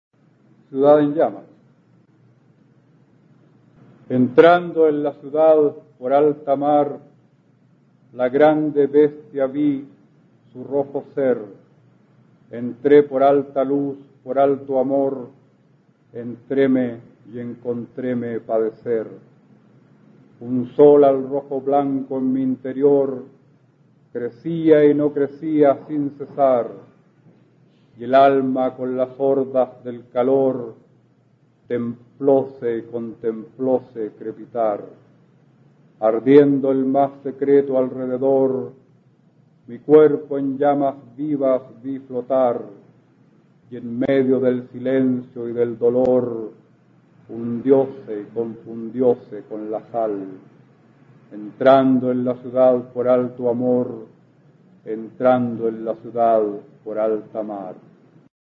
Aquí podrás escuchar al poeta chileno Óscar Hahn, perteneciente a la Generación del 60, recitando su soneto Ciudad en llamas, del libro "Imágenes nucleares" (1983).